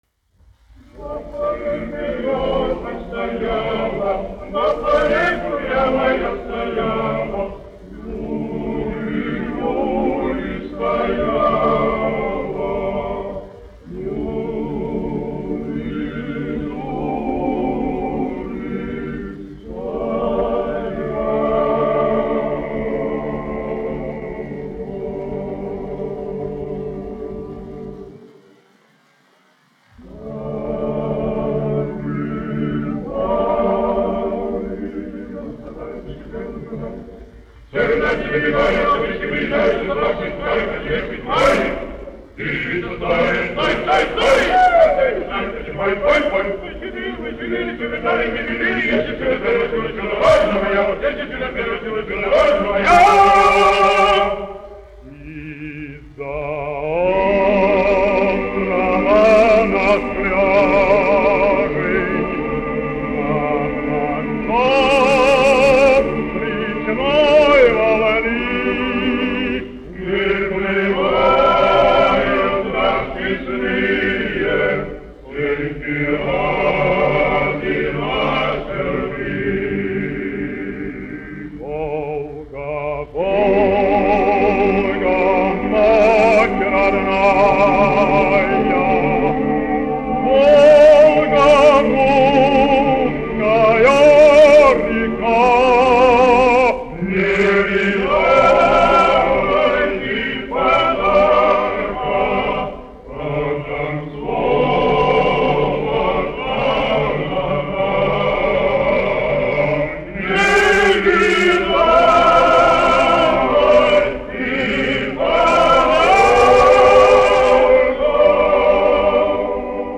Krievu - ukraiņu tautas dziesmu popūrijs
1 skpl. : analogs, 78 apgr/min, mono ; 25 cm
Krievu tautasdziesmas
Ukraiņu tautasdziesmas
Kori (vīru)